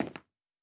added stepping sounds
Tile_Mono_03.wav